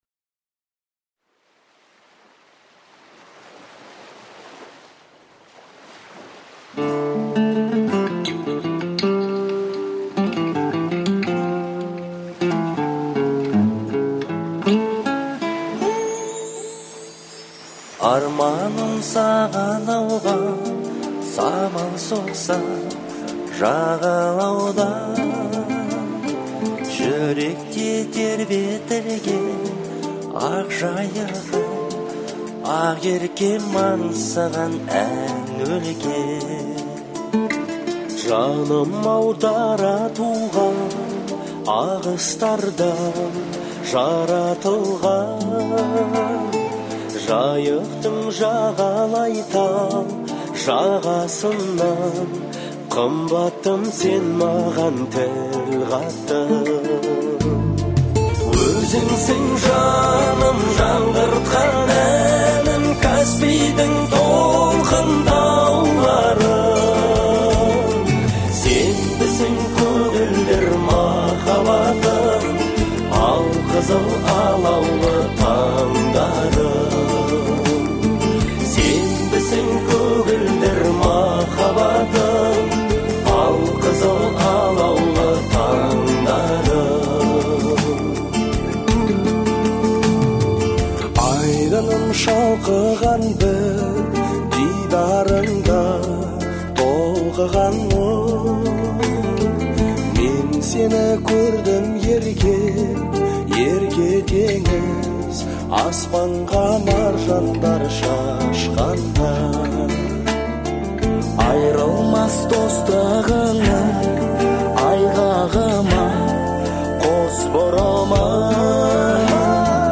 это песня казахской поп-музыки